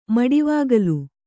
♪ maḍivāgalu